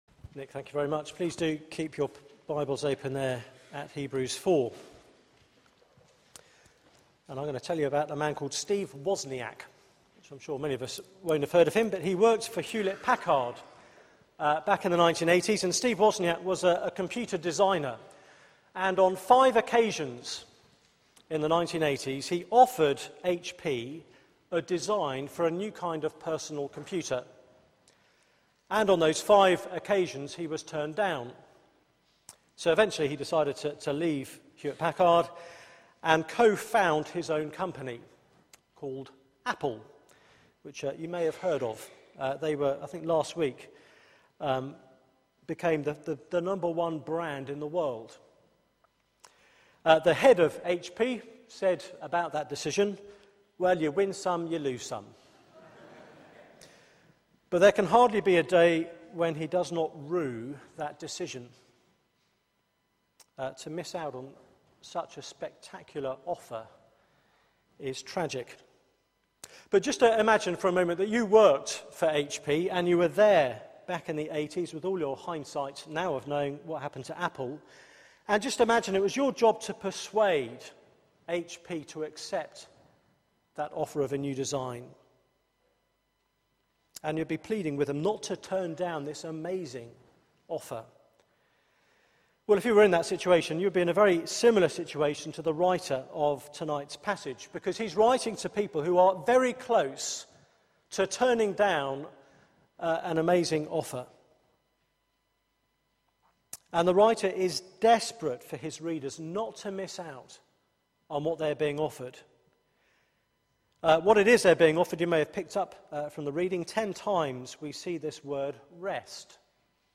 Media for 6:30pm Service on Sun 06th Oct 2013 18:30 Speaker
Series: Jesus is better Theme: Finding true rest Sermon